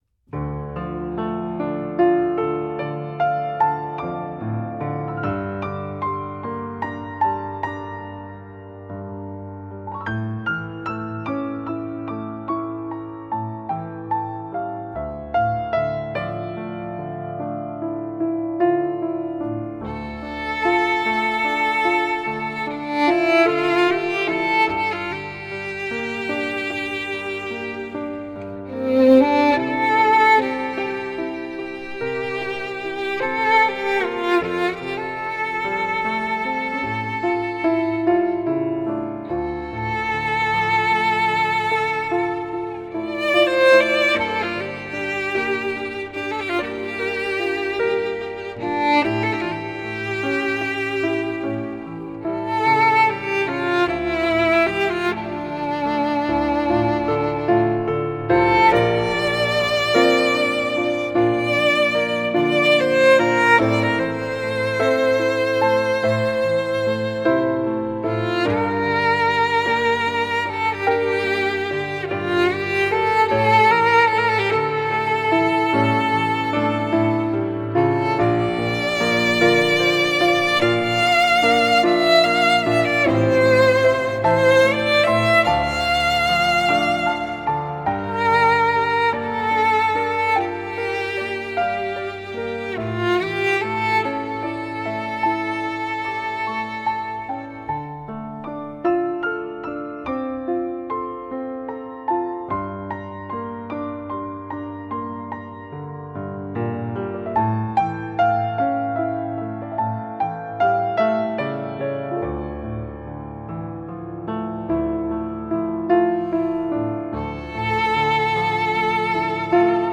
轻缓优美的旋律，流泄出法式的浪漫情调，
有种自然的美妙，柔亮而纯净，如同天籁之音直到心底。
演奏从容淡定 旋律婉约丰美 如诗般隽永 如爱般真诚